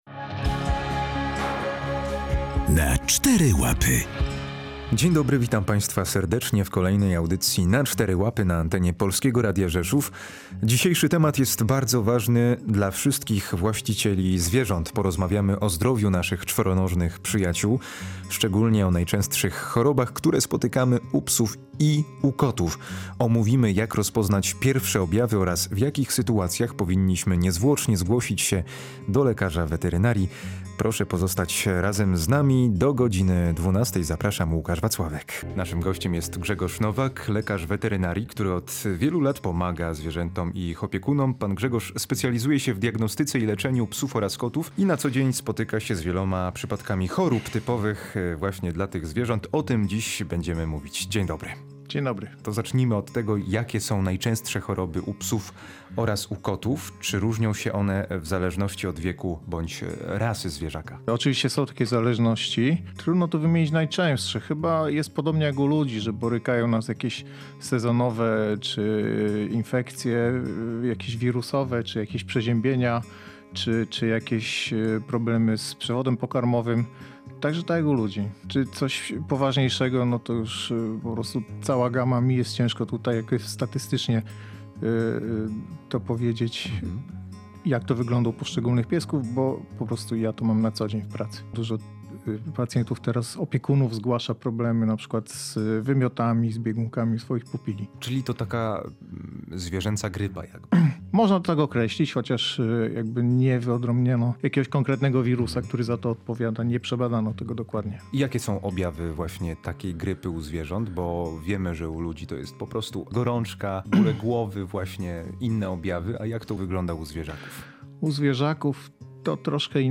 Audycje • Kiedy wizyta u weterynarza jest konieczna?